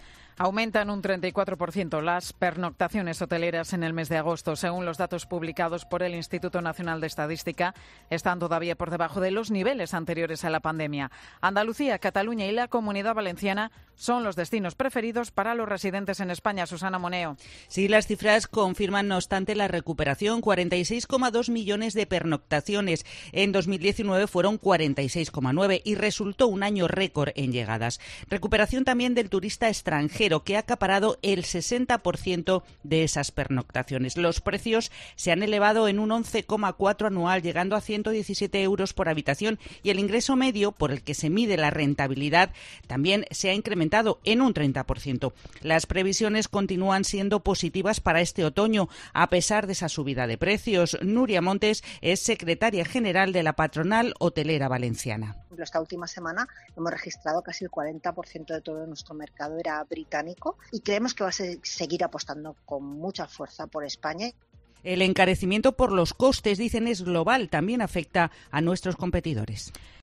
Las pernoctaciones en establecimientos hoteleros aumentaron un 34,3 % en agosto. Crónica